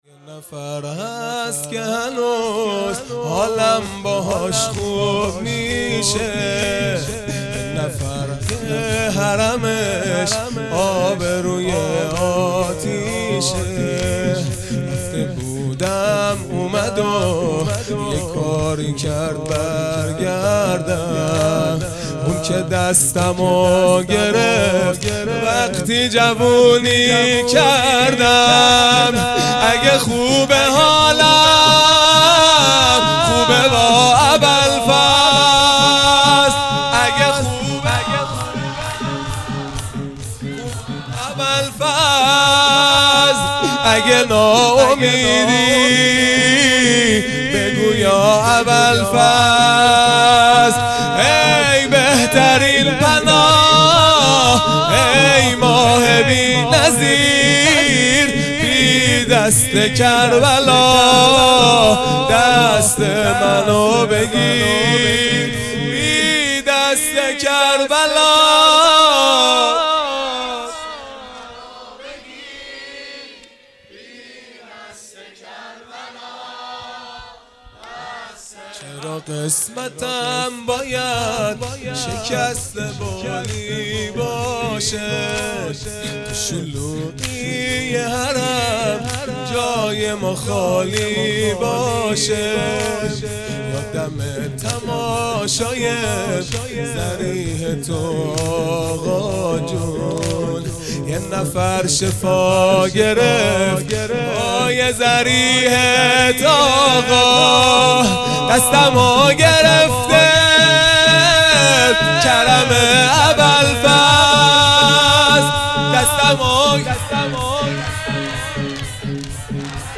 دهه اول محرم الحرام ۱۴۴۳ | شب تاسوعا | سه شنبه ۲6 مرداد ۱۴۰۰